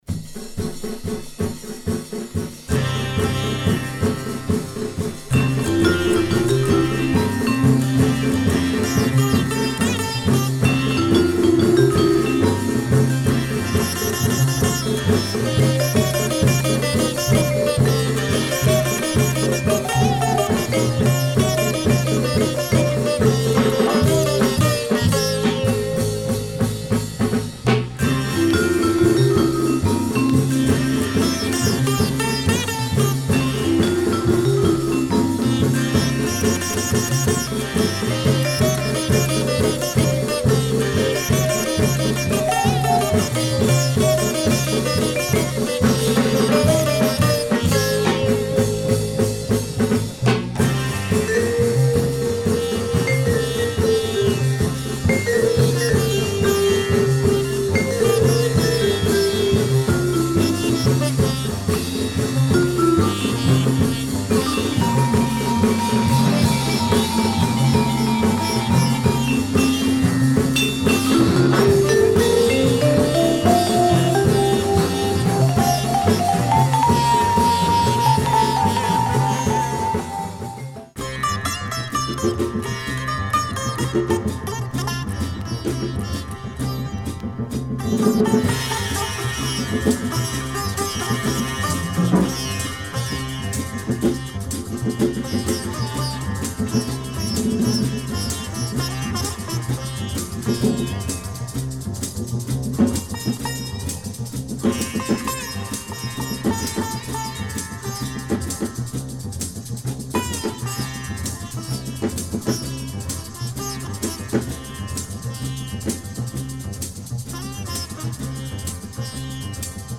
guitar and sitar